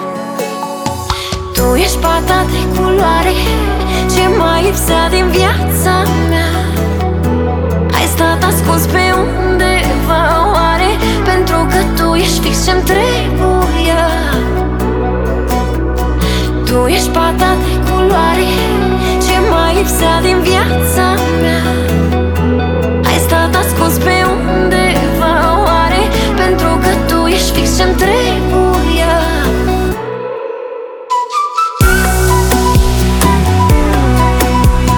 2025-05-13 Жанр: Поп музыка Длительность